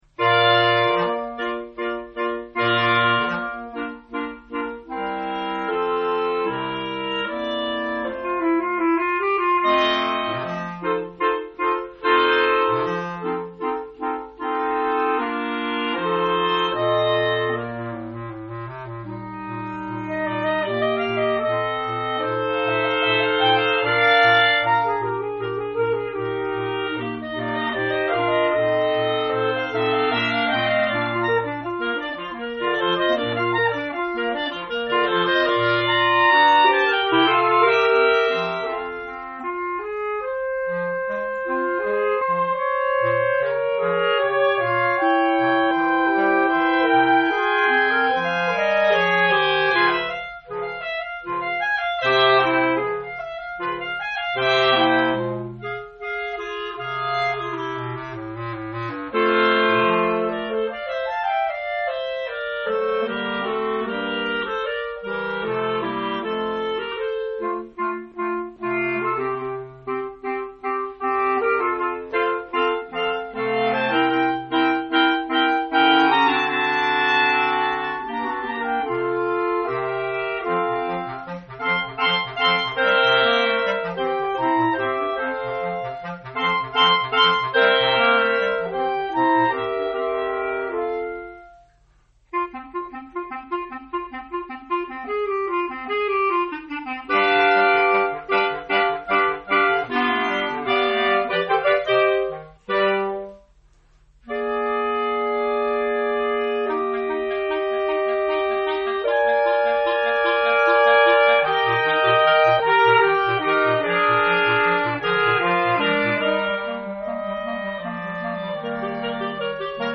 Voici quelques pièces moins connues pour ensemble de clarinettes, cors de basset ou clarinettes de basset.
pour 2 clarinettes et 3 cors de basset (4'09)
clarinettes